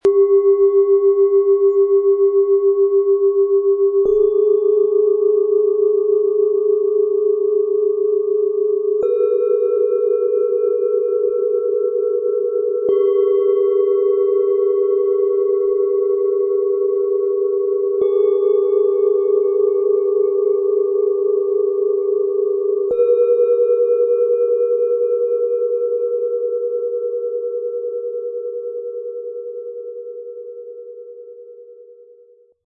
Spüre deine Wurzeln, vertraue deinem Gefühl und wachse frei - Set aus 3 Planetenschalen in schwarz-gold, Ø 11,6 -13 cm, 1,35 kg
Die Schalen dieses Sets schwingen zusammen und schaffen eine tiefe Resonanz, die dich erdet, dein Gefühl weckt und dir Raum für freies Wachstum gibt.
Im Sound-Player - Jetzt reinhören können Sie den natürlichen, warmen Klang jeder Schale erleben und die besonderen Töne dieses Sets hautnah anhören.
Der beiliegende Klöppel bringt die Schalen sanft zum Klingen und erzeugt einen angenehmen, harmonischen Ton.
Tiefster Ton: Tageston
Mittlerer Ton: Neptun
Höchster Ton: Lilith